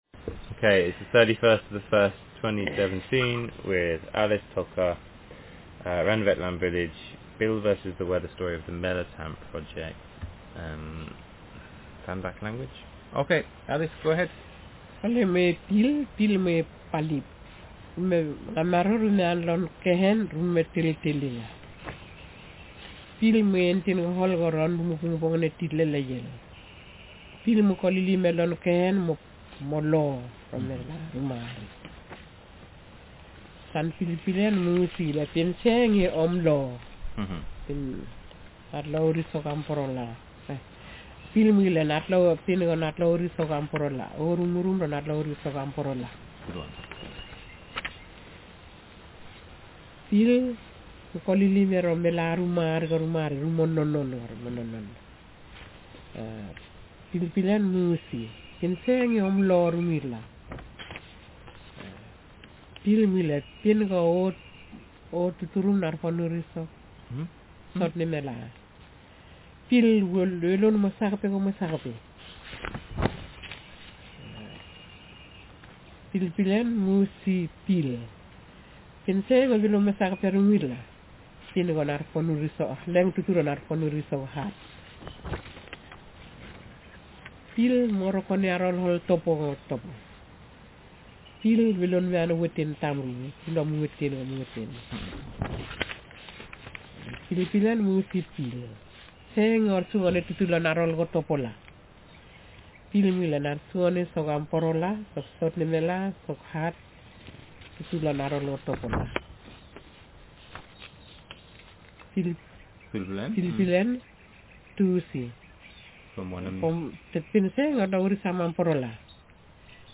Speaker sexf
Text genrestimulus retelling
doreco_orko1234_SB_AT1_weather.mp3